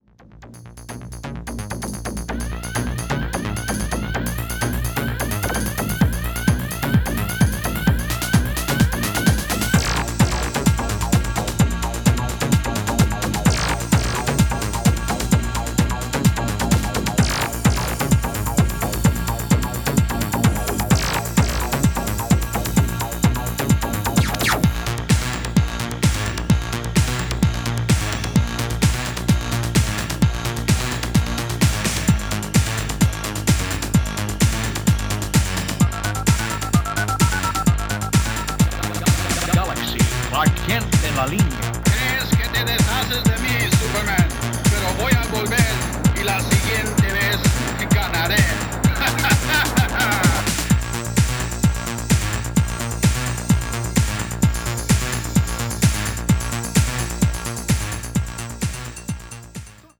本作も御多分に洩れず80年代から地続きなゴリゴリのハードビートが鳴ってます。